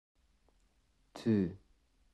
Тт_–_ukrainian.ogg.mp3